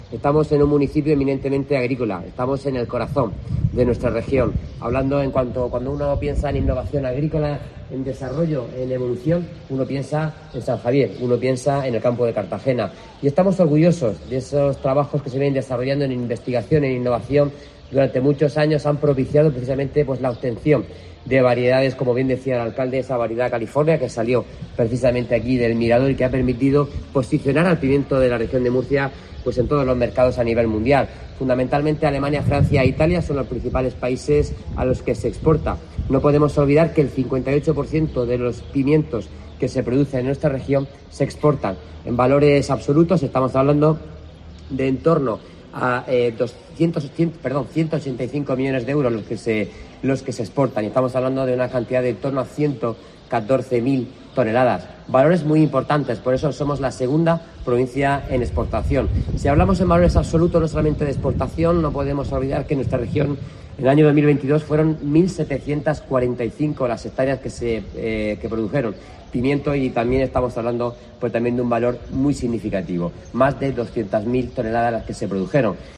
Antonio Luengo, consejero de Agua, Agricultura, Ganadería y Pesca
Así lo ha puesto de manifiesto el consejero de Agua, Agricultura, Ganadería y Pesca, Antonio Luengo, durante la presentación de la II Jornada Gastronómica del Pimiento, acto en el que estuvo acompañado por el alcalde de la localidad, José Miguel Luengo.